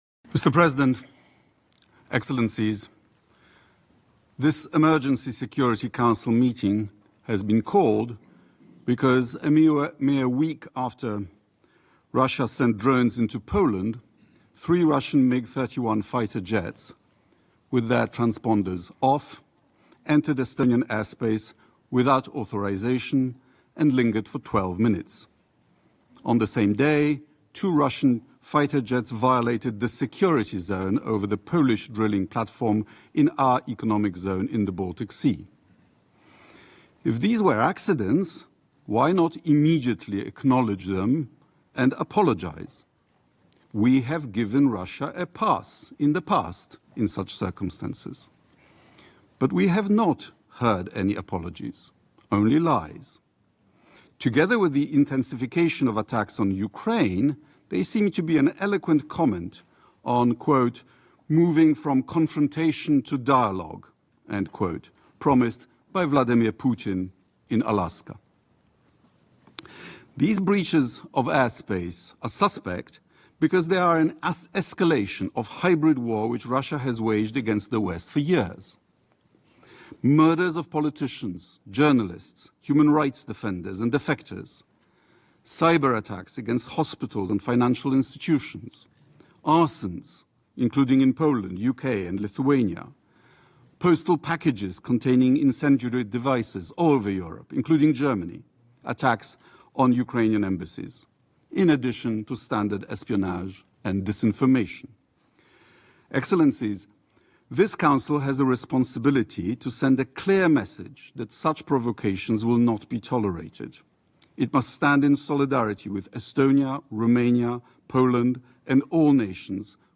Remarks at an Emergency Meeting of the UN Security Council on Russian Incursion into NATO Airspace
delivered 22 September 2025, United Nations Headquarters, New York, NY